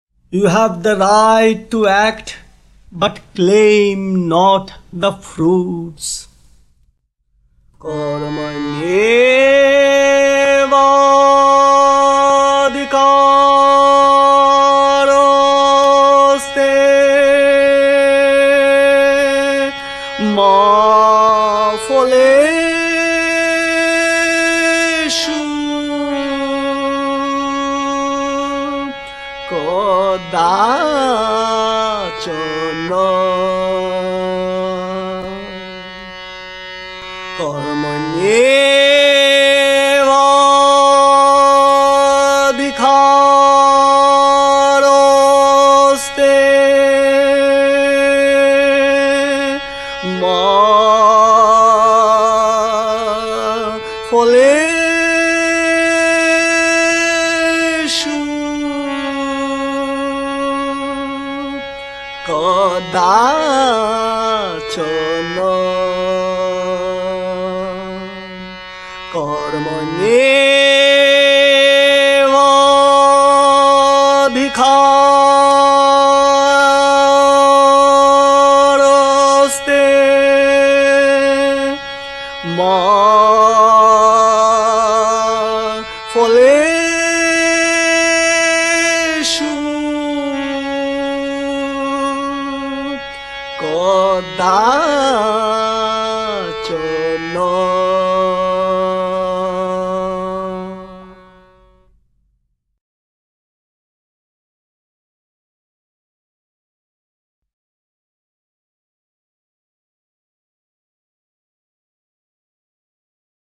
In dieser Aufnahme aus den 70er Jahren rezitiert und singt Sri Chinmoy unsterbliche Sanskrit-Mantras und Passagen aus den Upanishaden und der Bhagavad Gita. Sri Chinmoy begleitet sich selbst auf dem Harmonium.
Das kraftvolle und seelenvolle Singen dieser uralten Mantras kann den Zuhörer in die uralte spirituelle Inspiration führen, die mit diesen Mantras verbunden ist – Mantras, die von Sehern und Rishis über die Jahrhunderte hinweg rezitiert wurden. Einige der Lieder verwenden traditionelle Melodien.